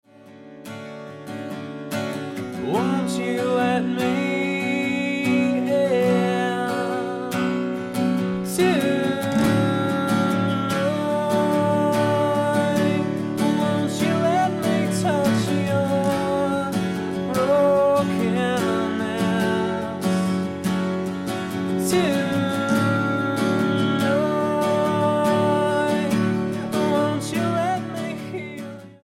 STYLE: Pop
is gentle acoustic